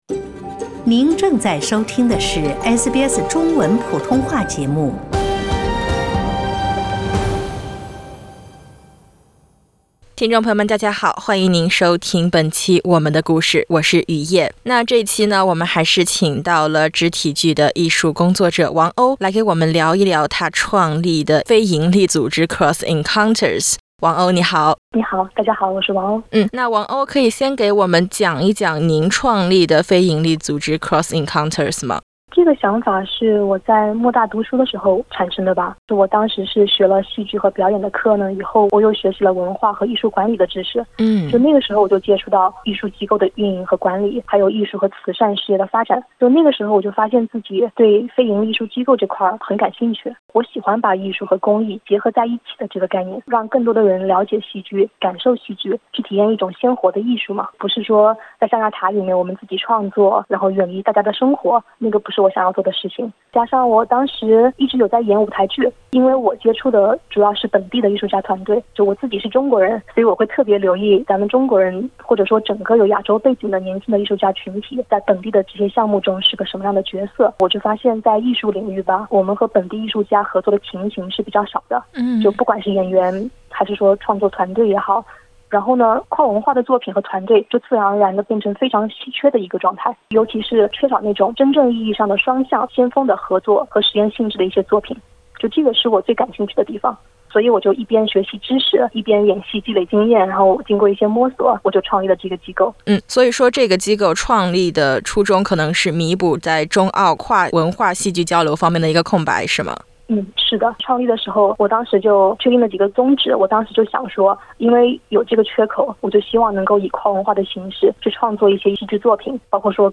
欢迎您点击封面音频，收听完整采访。